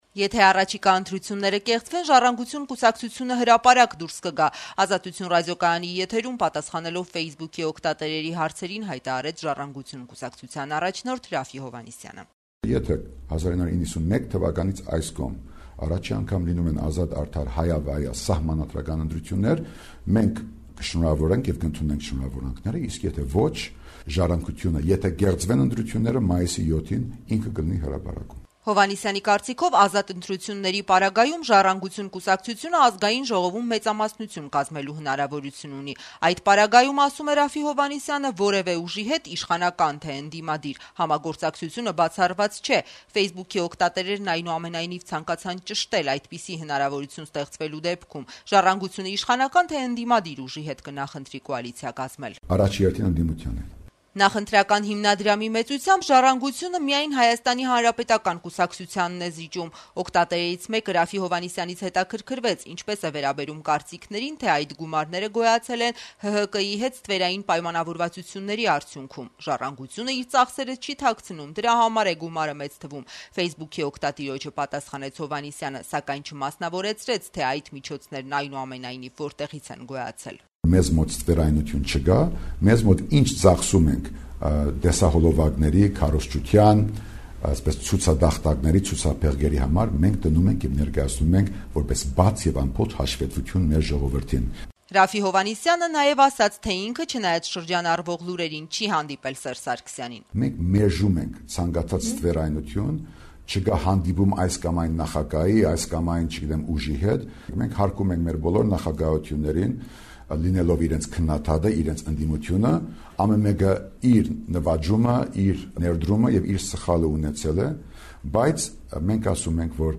«Ժառանգություն» կուսակցության հիմնադիր եւ առաջնորդ Րաֆֆի Հովհաննիսյանը «Ազատություն» ռադիոկայանի եթերում պատասխանել է Facebook-ի օգտատերերի հարցերին: